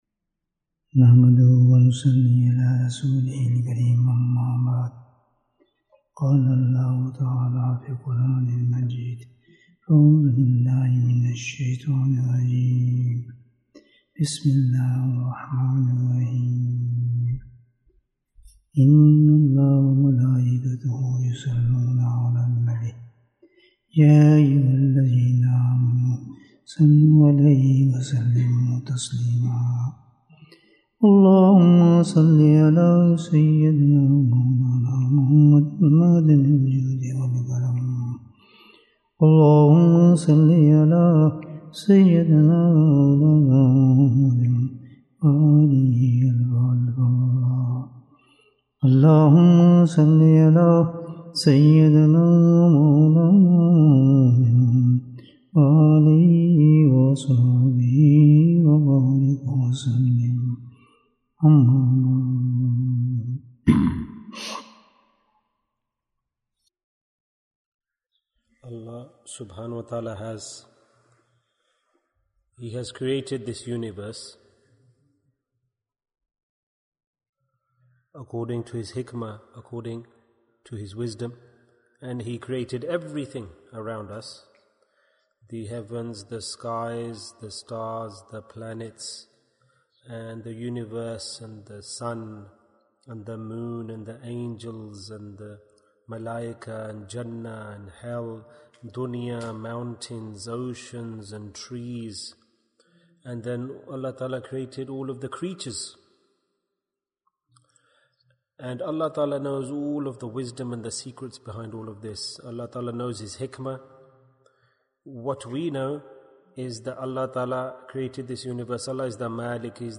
Which Two Deeds has Allah Ordered? Bayan, 51 minutes17th November, 2022